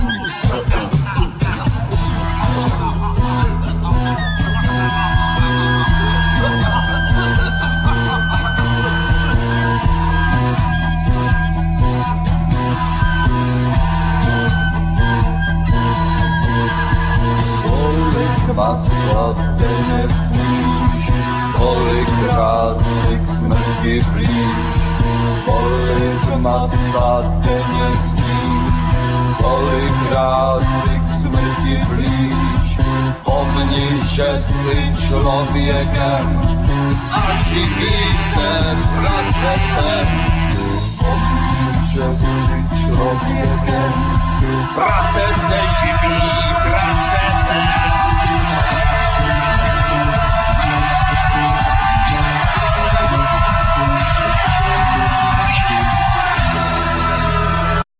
Vocals,Guitar
Bass,Vocals
Drums
Percussions
Tennor saxophone,Vocals